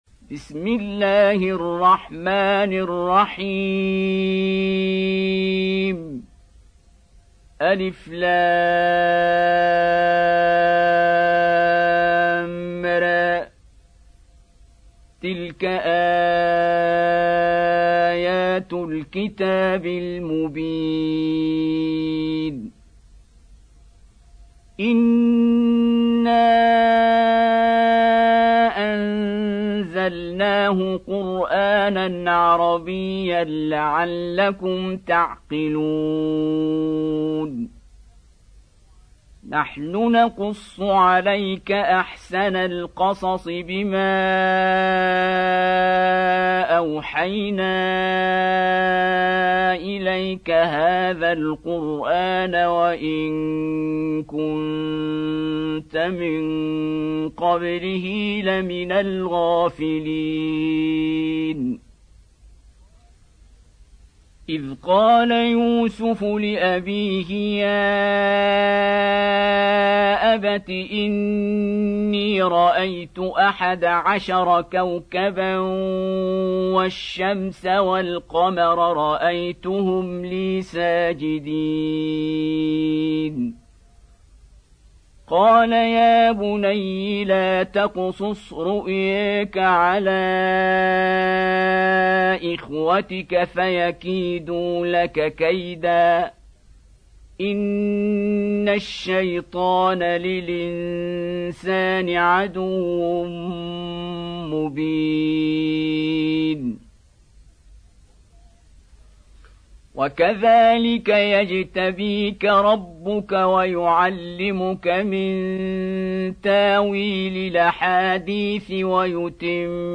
Surah Yusuf Beautiful Recitation MP3 Download By Qari Abdul Basit in best audio quality.
Surah-Yusuf-quran.mp3